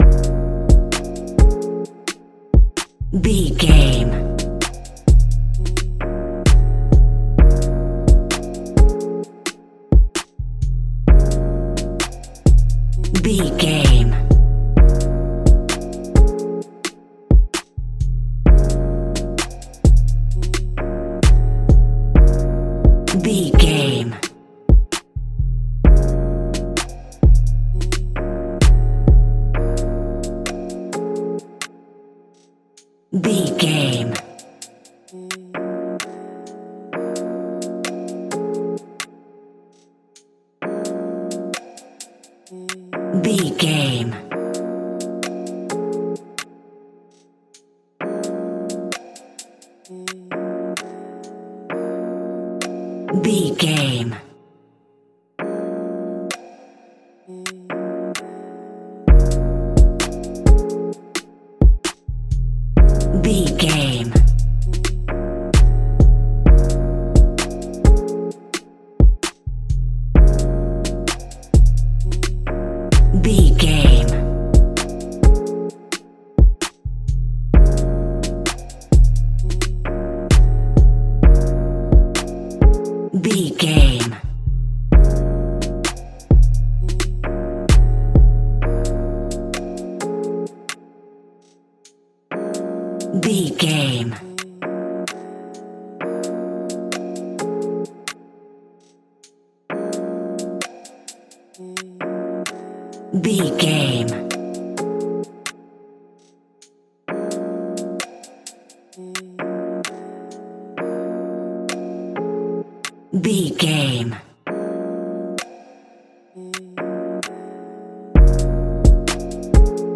Ionian/Major
drums
relaxed
smooth
mellow
soothing